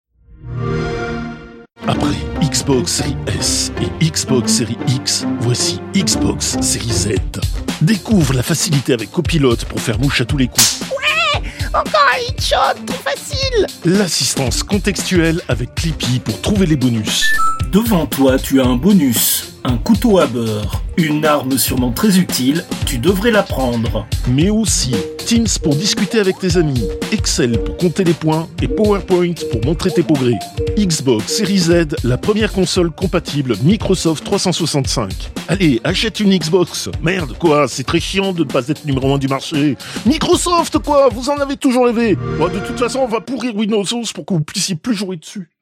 Fausse publicité : X-Box série Z